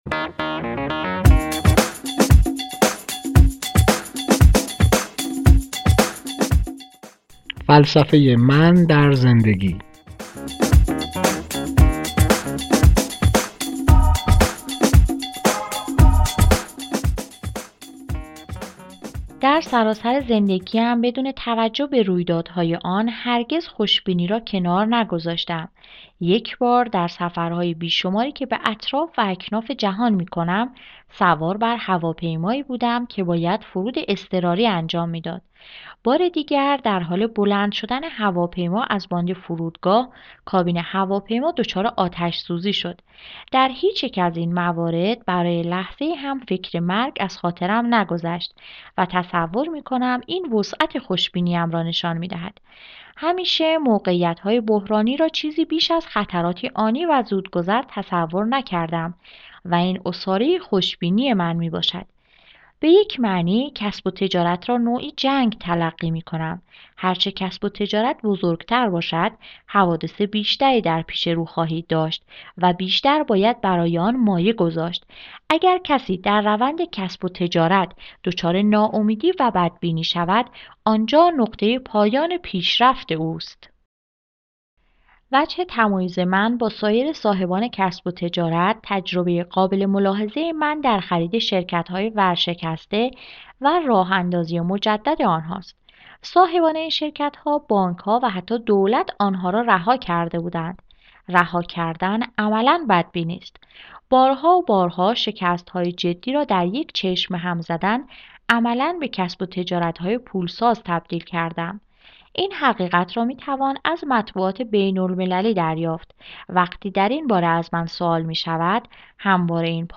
کتاب های صوتی سینرژی